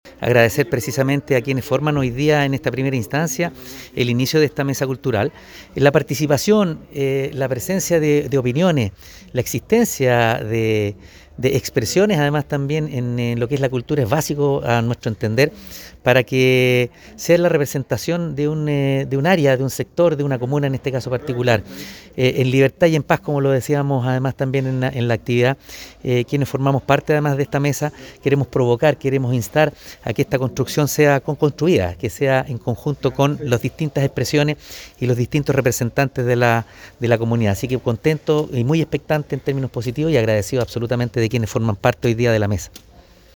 “Quienes formamos parte de esta mesa de cultura queremos instar a que este proyecto sea co-construído en conjunto con las distintas organizaciones, artistas y representantes de la cultura de toda la comuna”, comentó Óscar Calderón Sánchez, alcalde de Quillota, en su discurso durante la ceremonia.
Alcalde-Oscar-Calderon-Sanchez-1-3.mp3